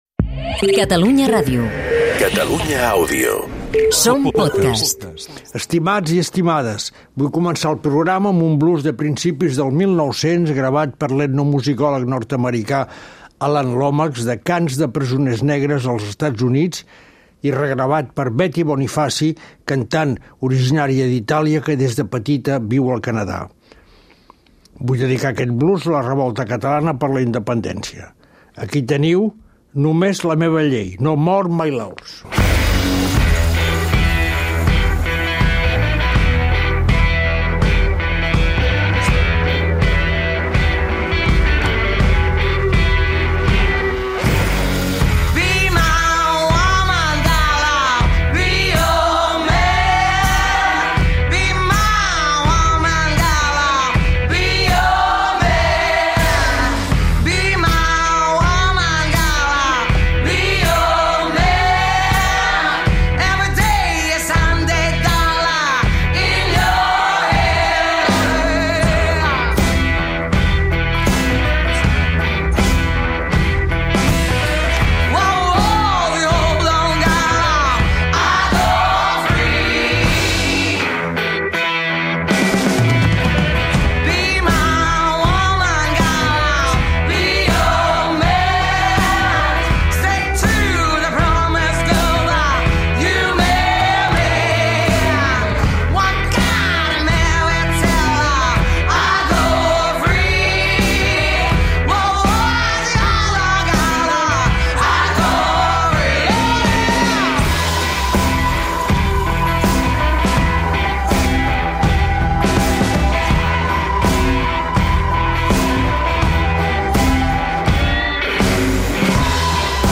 Lluís Coloma és el pianista català de Blues i Boogie Woogie reconegut a Europa i Àsia com un virtuós en aquests estils. La seva música, única, l'ha convertit en un excepcional compositor que combina el blues i el jazz amb influències musicals catalanes. És el convidat d'aquesta setmana.
Quico Pi de la Serra interpreta en directe Blues Fet, el blues que ha compost per a aquest programa.